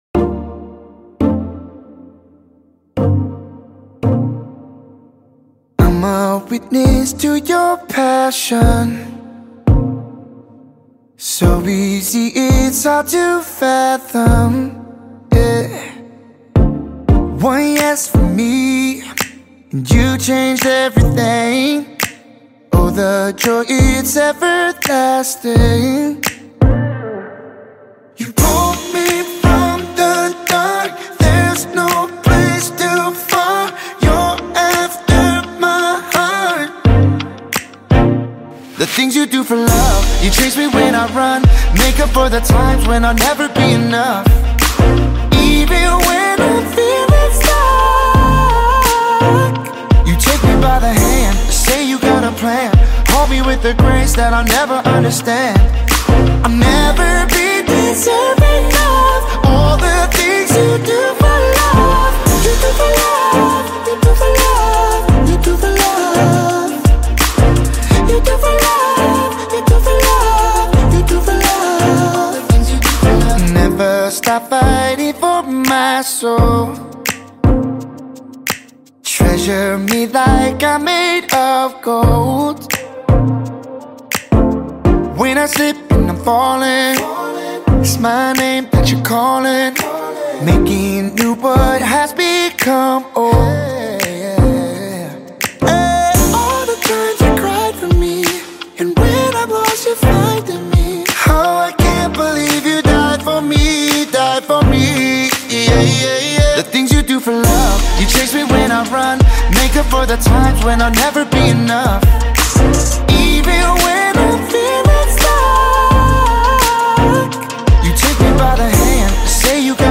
The new, anthemic song is available here to download/stream.